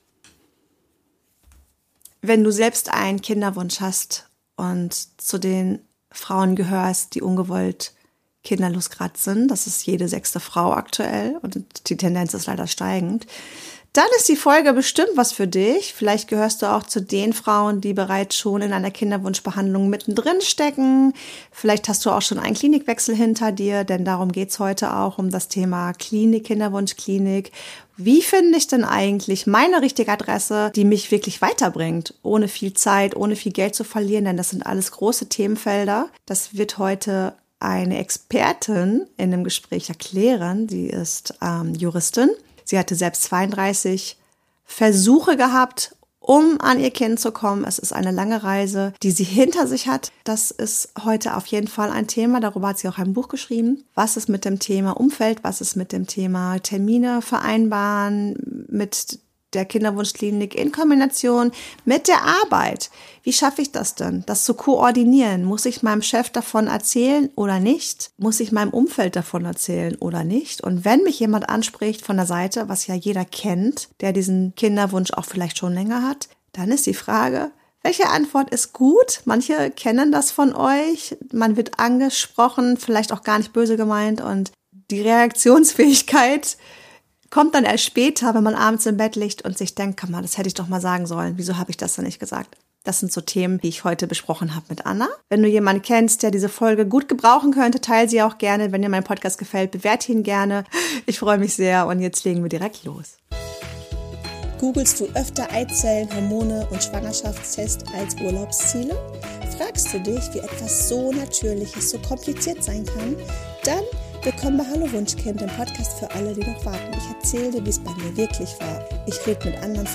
Ein ehrliches Gespräch voller Erfahrungen, praktischer Tipps und Mut, den eigenen Weg zu gehen.